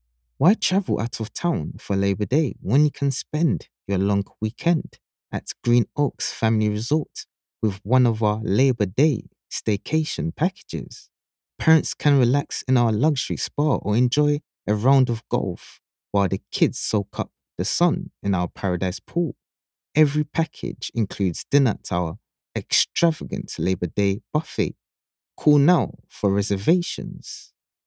Real, fresh and conversational for commercials, imaging and tv and radio promo, confident and caring form narrations.